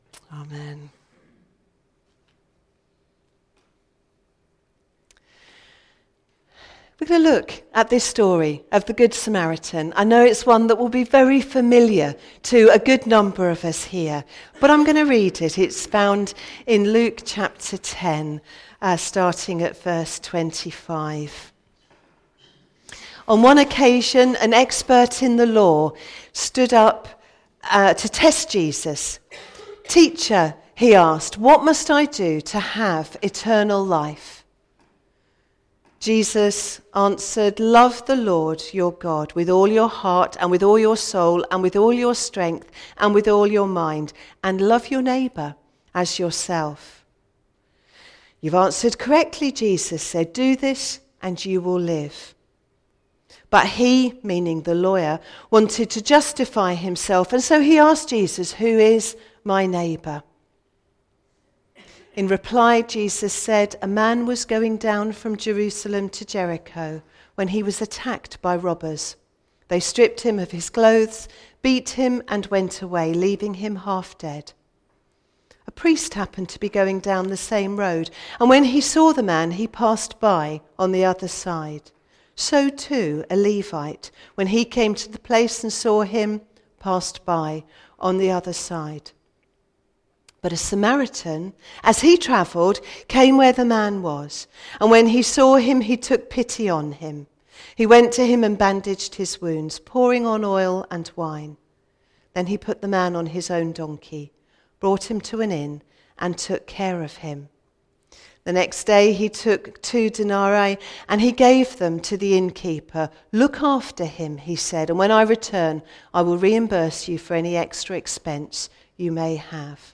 A message from the series "Stand Alone Sermons (2019)."